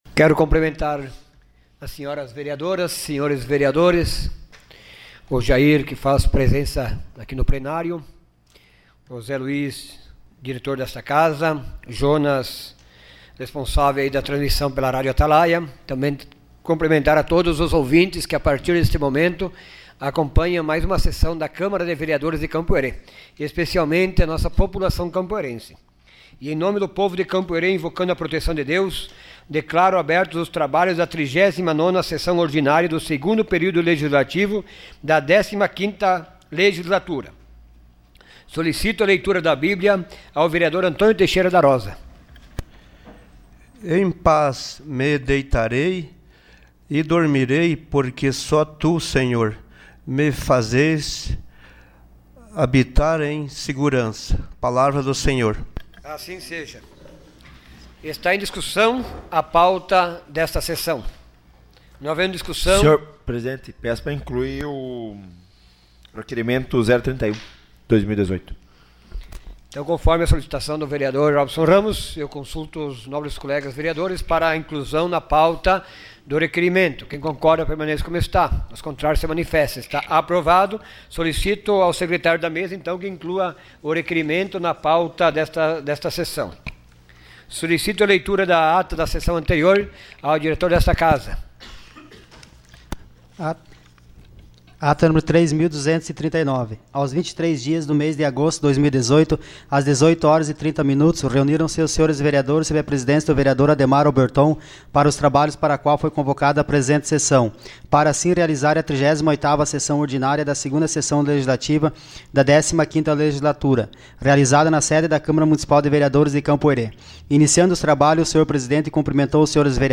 Sessão Ordinária dia 27 de agosto de 2018.